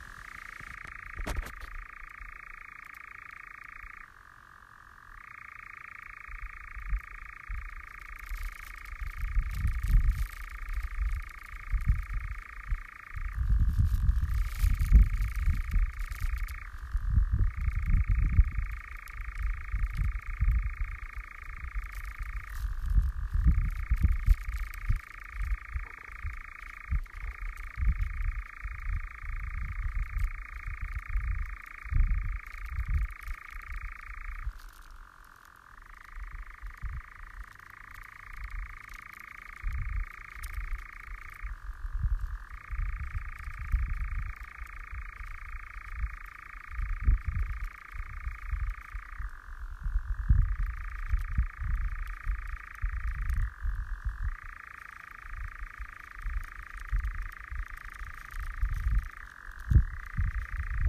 I had never heard a nightjar before but as I walked further into the woods the repetitive cricket-like churring became louder and louder.
I returned to the nightjars, which were now louder than before.
nightjar5.m4a